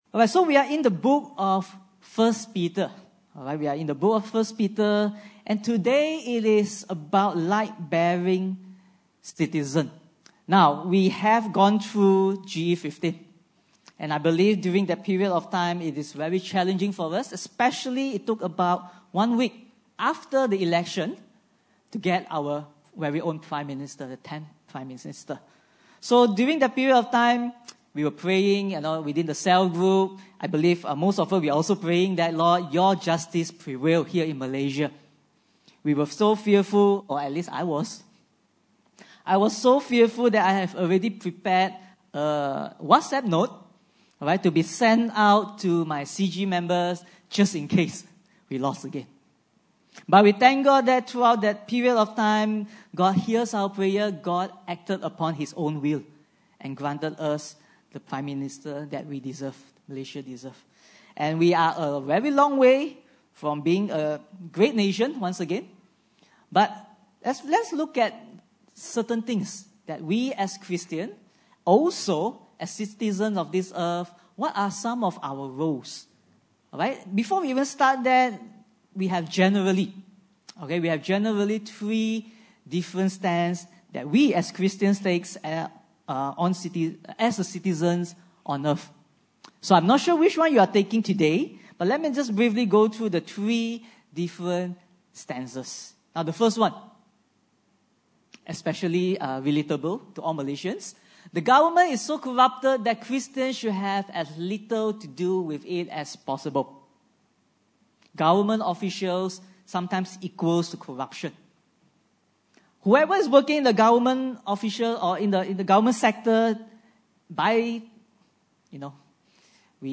1 Peter 2:13-17 Service Type: Sunday Service (Desa ParkCity) « Is Jesus